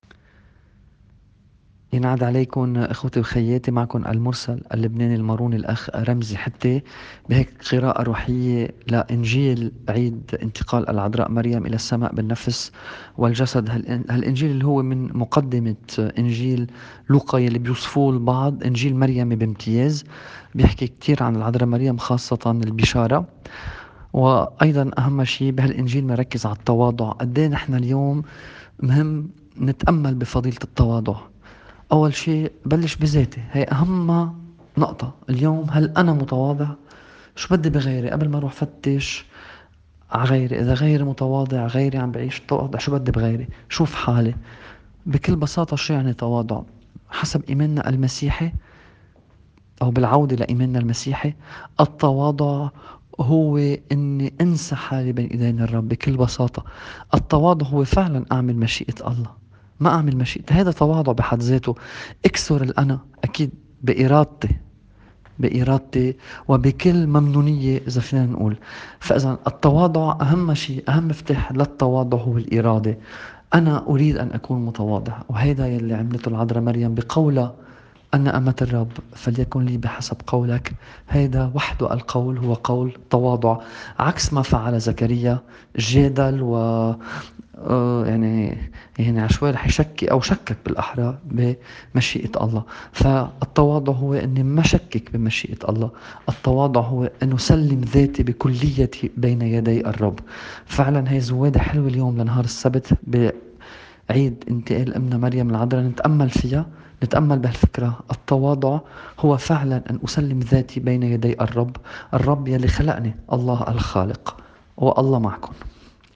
تأمّل في إنجيل ١٥ آب ٢٠٢٠.mp3